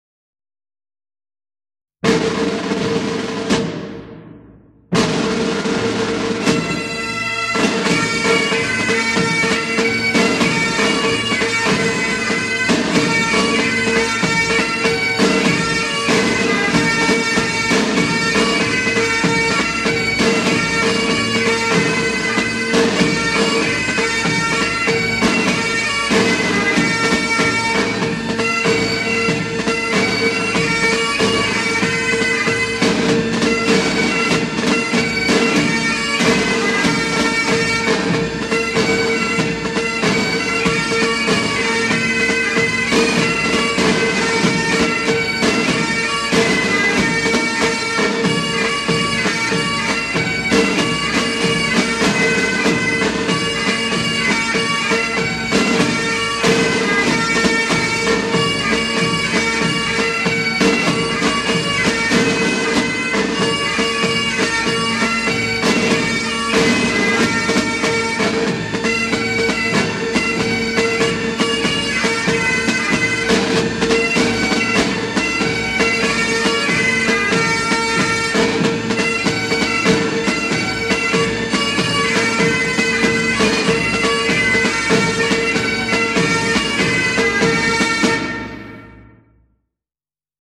0072-风笛名曲军队进行曲.mp3